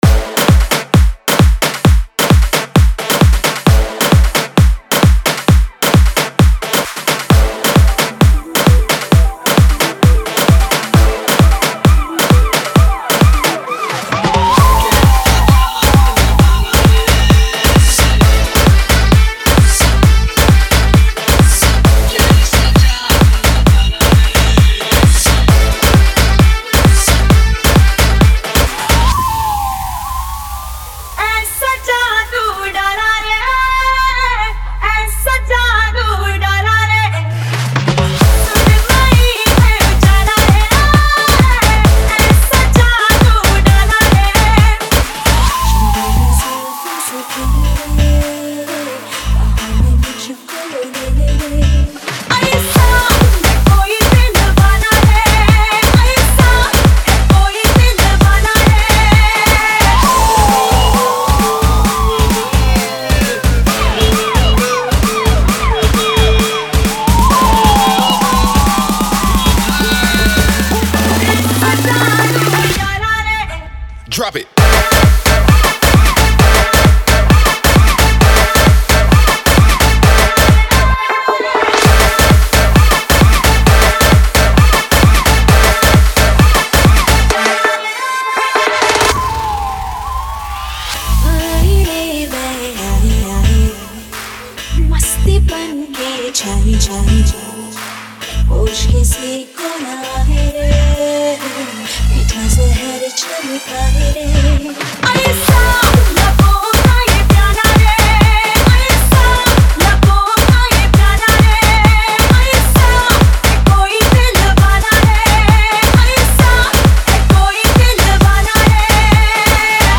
2024 Bollywood Single Remixes Song Name